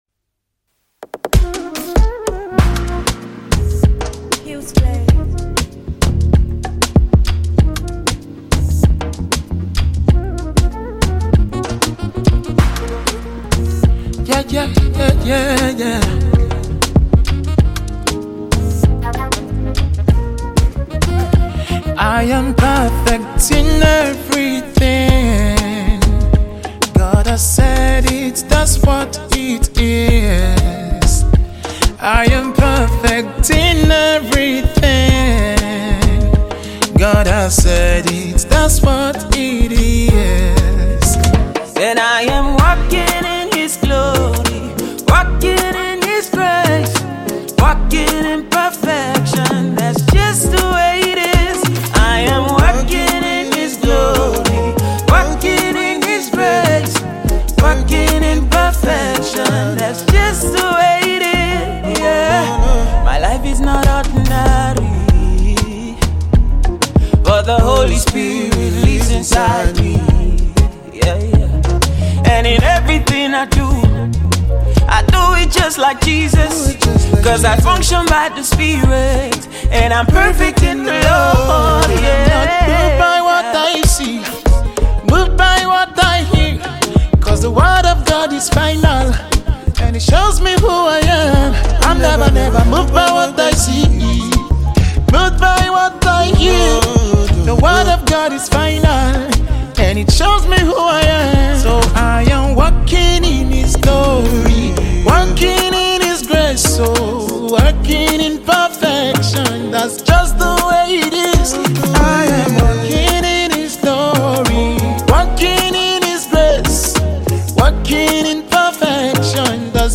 Sensational Gospel singer